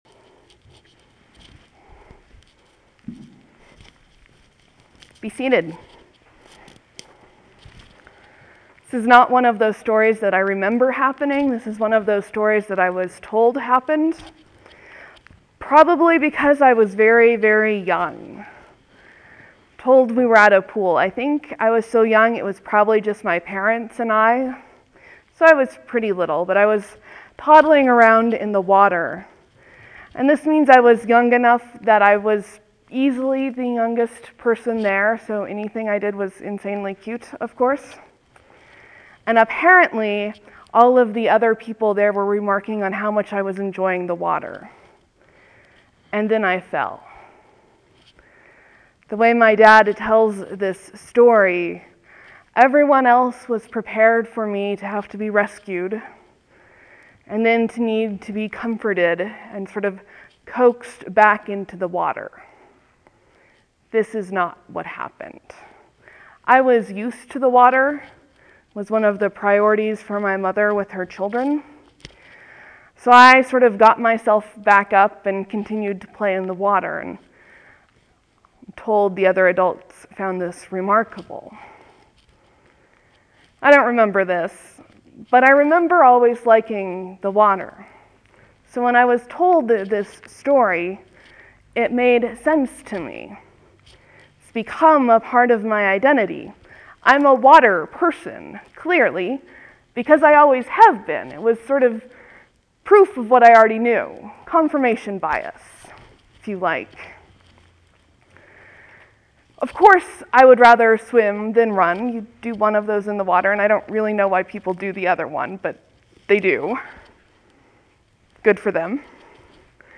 Easter, Sermon, , , , , , 1 Comment